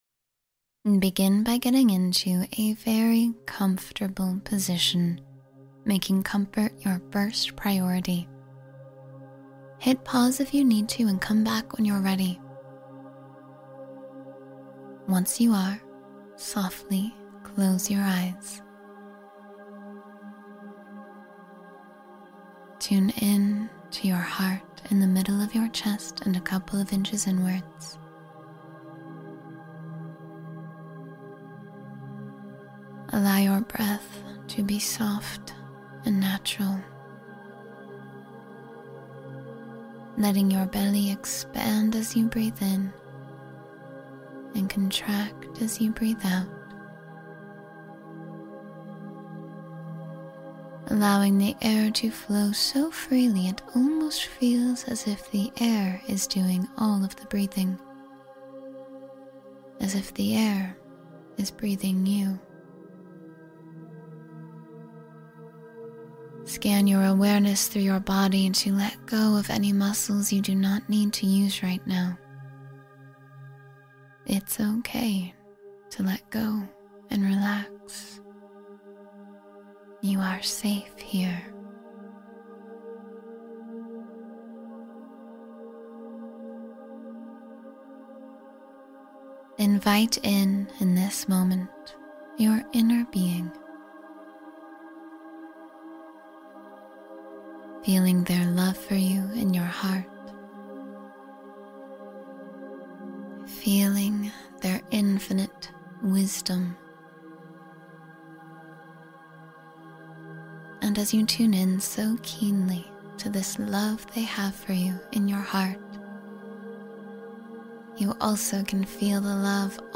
Drift into Healing, Restful Sleep — Guided Meditation for Deep Relaxation